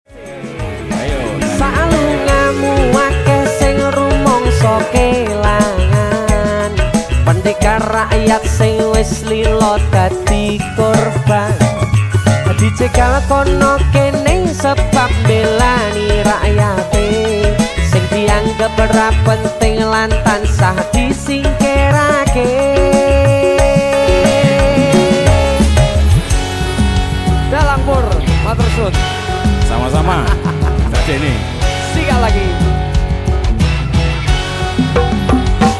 DC MUSIC Live Jepara